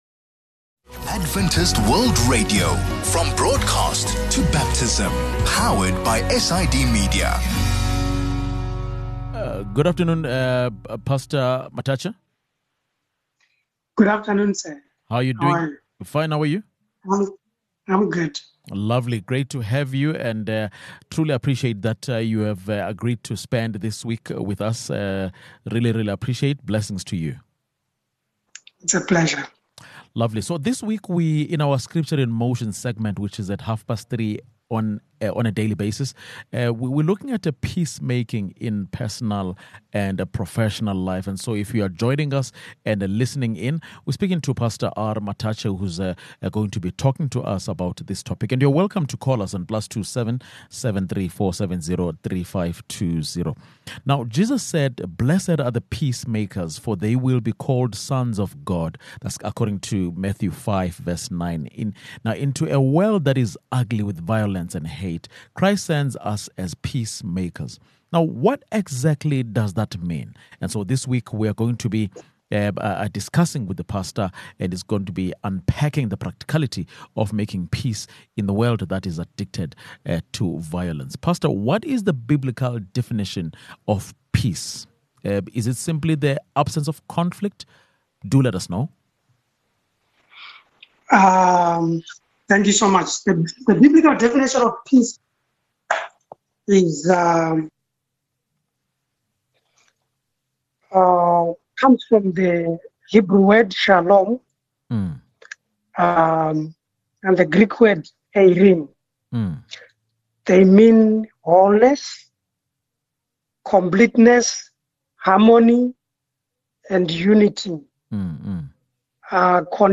In this introductory conversation, we lay down the basic definitions of peace, quiet, and conflict. Can ‘peace’ and ‘quiet’ be used interchangeably?